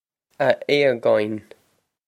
Pronunciation for how to say
Ah Ay-gawin
This is an approximate phonetic pronunciation of the phrase.